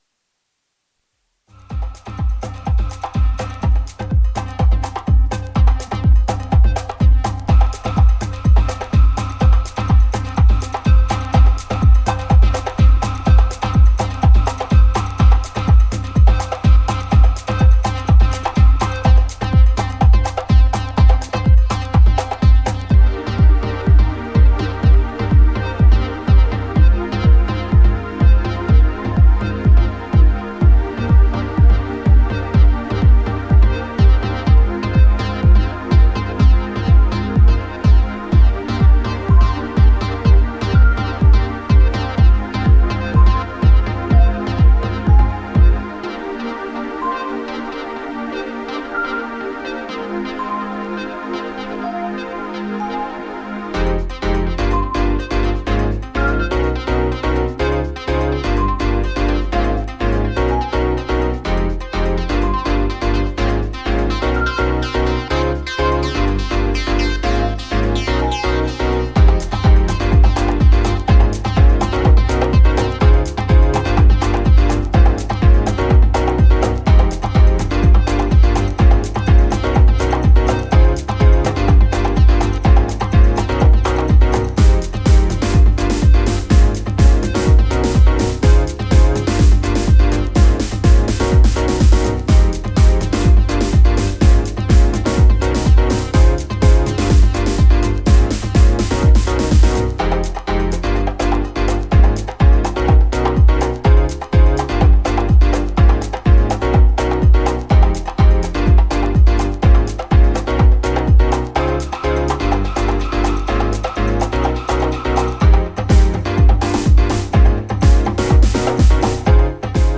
軽やかで高揚感のあるハウスを6分半ほどの尺で繰り広げていった、フロアでの反応も抜群だろう会心の仕上がりとなっています。
ジャンル(スタイル) HOUSE